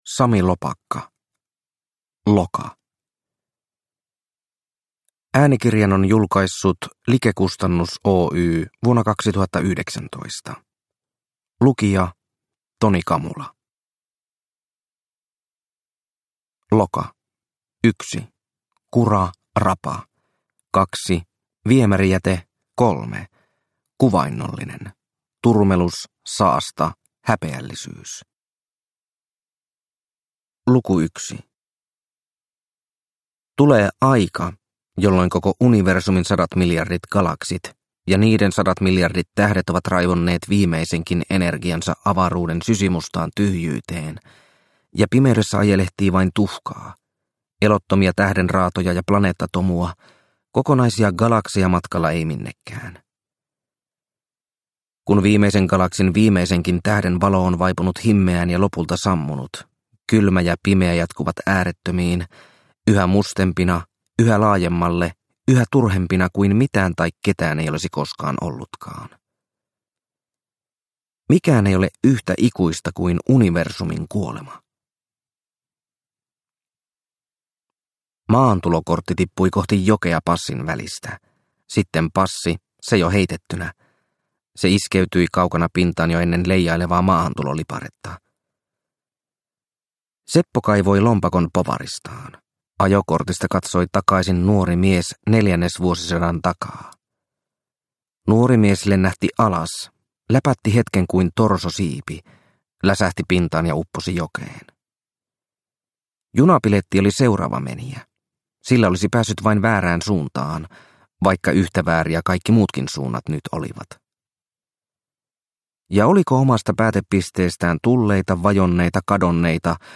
Loka – Ljudbok